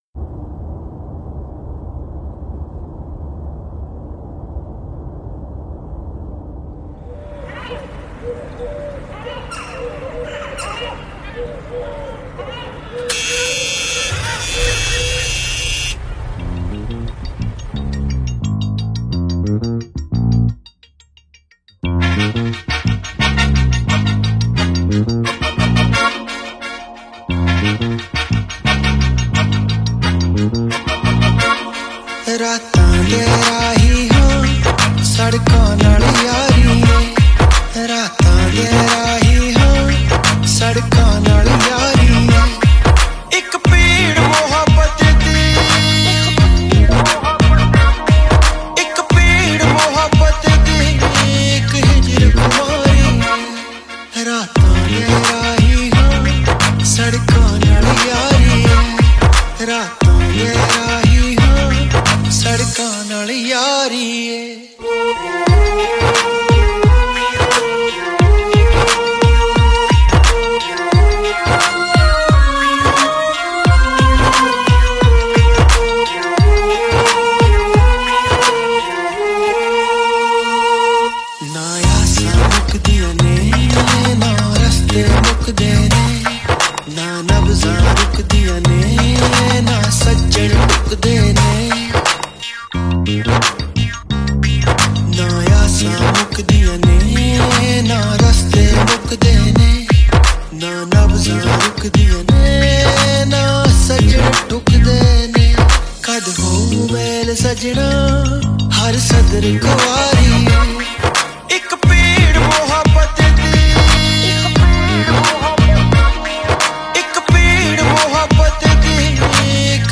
Punjabi Mp3 Songs